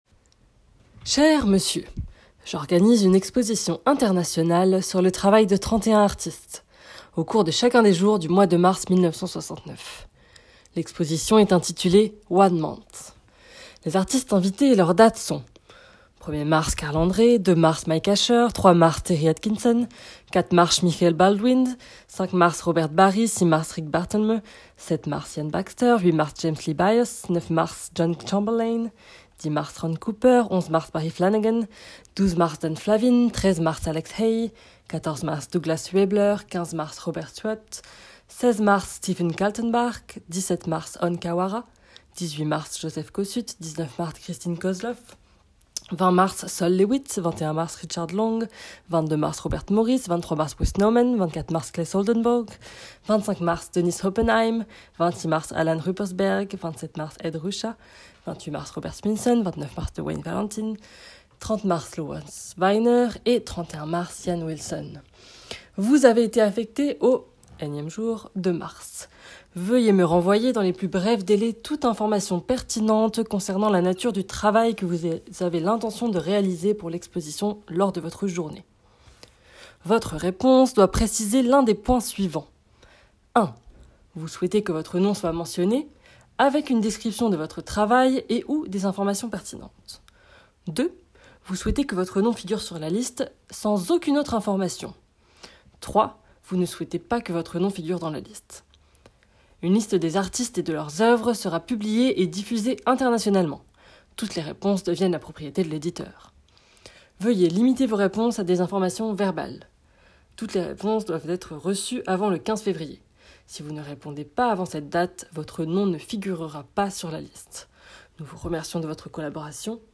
Lecture de la lettre